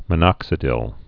(mə-nŏksĭ-dĭl)